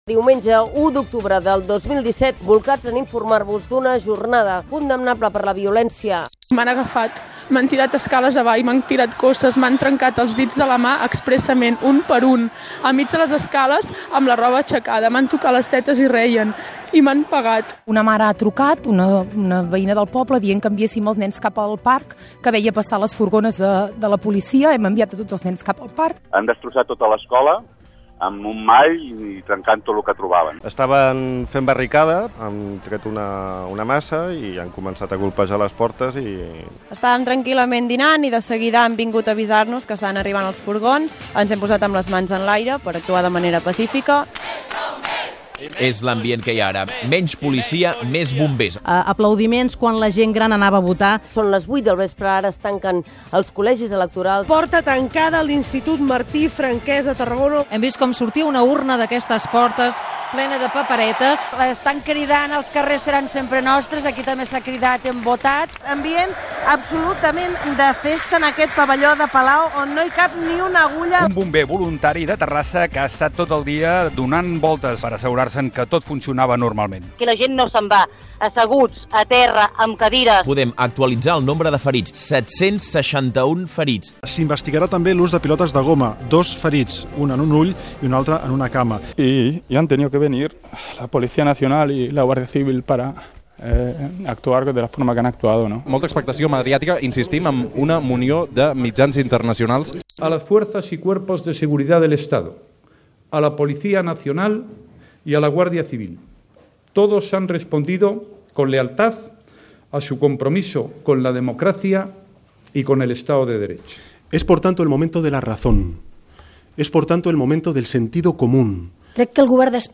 Resum del referèndum d'autodeterminació unilateral convocat per la Generalitat de Catalunya el dia 1 d'octubre: testimonis de la violència policial, declaracions de polítics, fragments de cròniques periodístiques
Informatiu